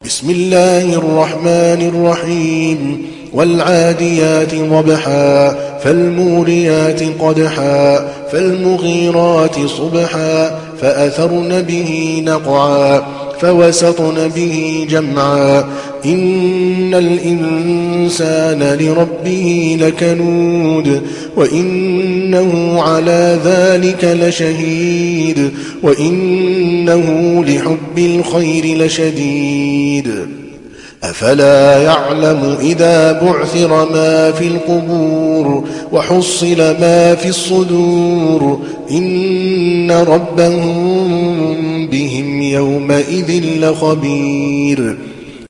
تحميل سورة العاديات mp3 بصوت عادل الكلباني برواية حفص عن عاصم, تحميل استماع القرآن الكريم على الجوال mp3 كاملا بروابط مباشرة وسريعة